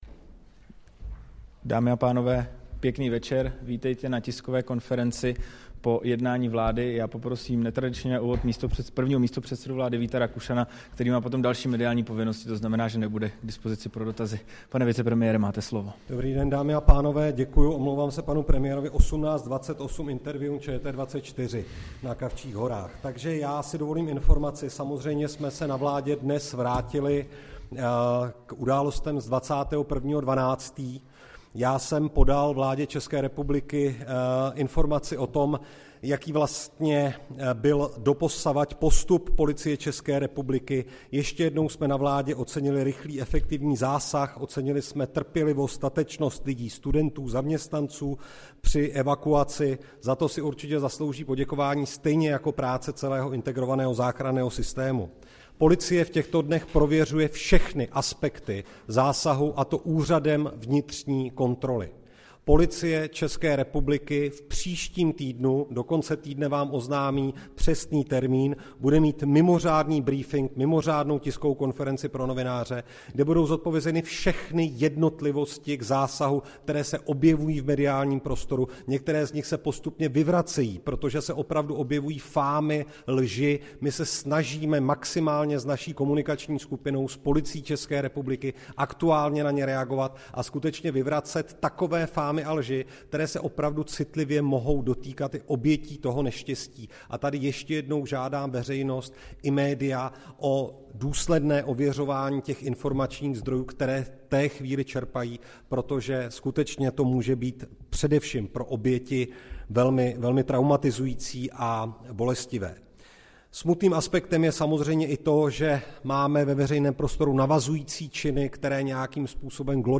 Tisková konference po jednání vlády, 3. ledna 2024